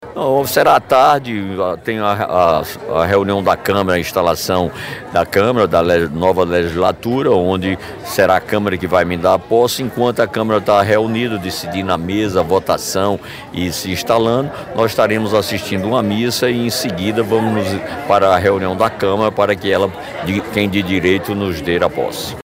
Os comentários foram registrados pelo programa Correio Debate, da 98 FM, de João Pessoa, nesta quinta-feira (26/12).